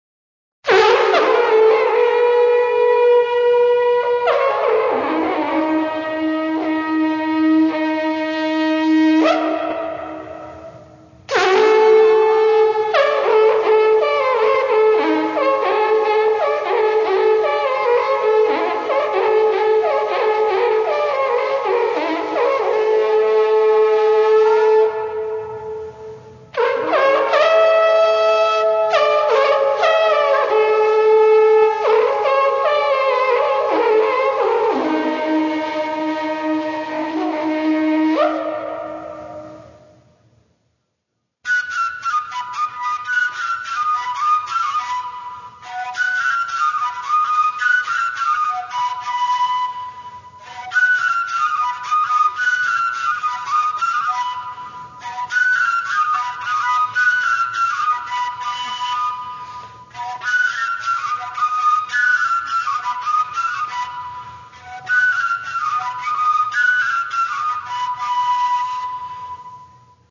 Каталог -> Народная -> Инструментальная